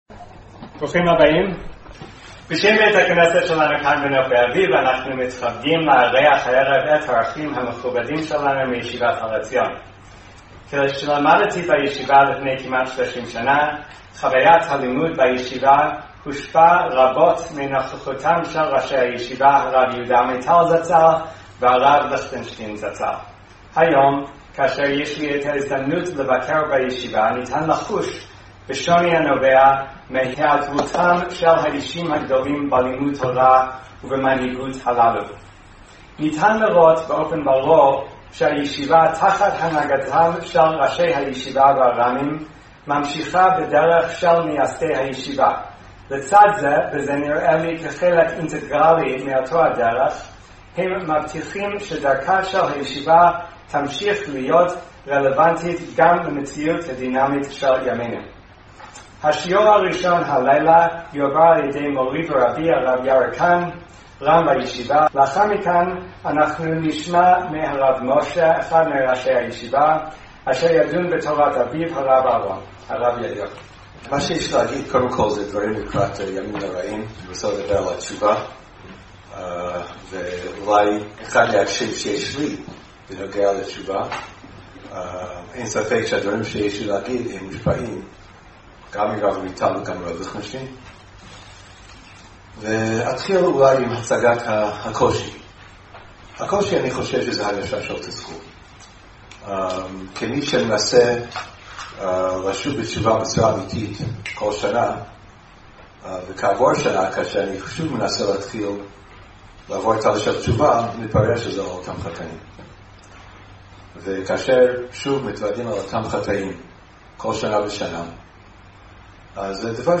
השיחה הועברה בח' באלול תשע"ו בבית הכנסת נופי אביב בבית שמש